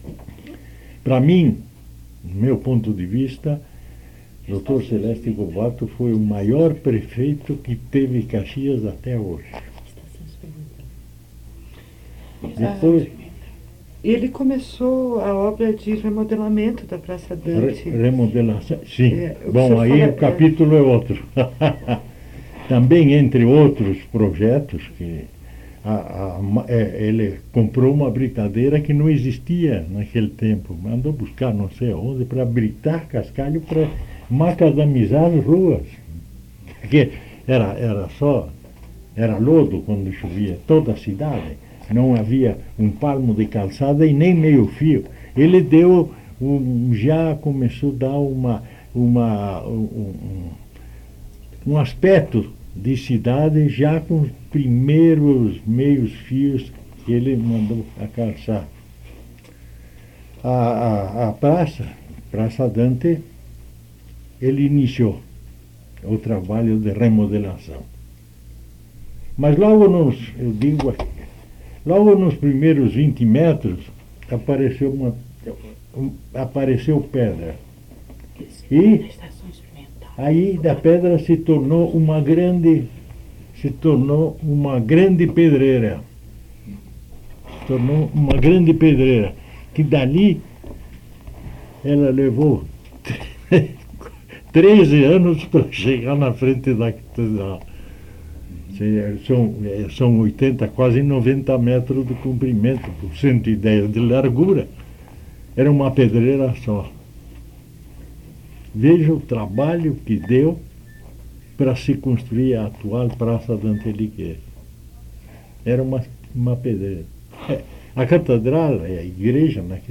Trecho de áudio da entrevista